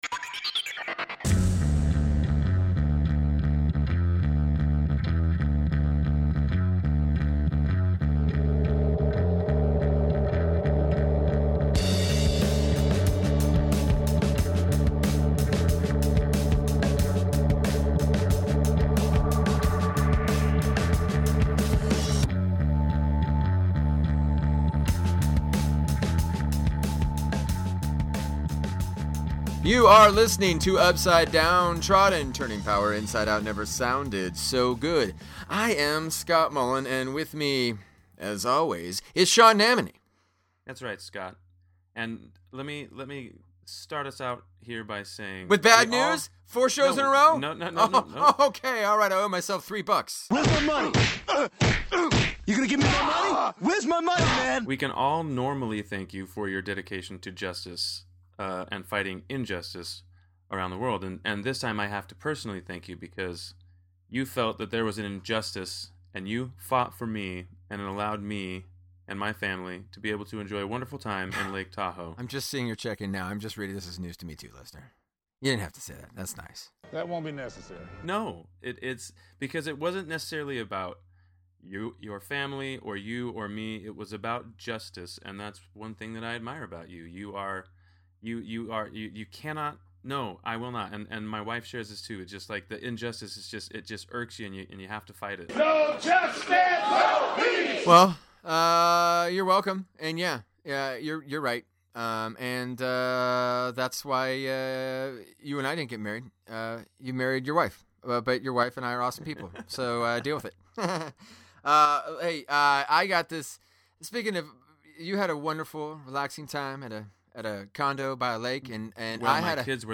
Informative and entertaining talk about the issues that matter.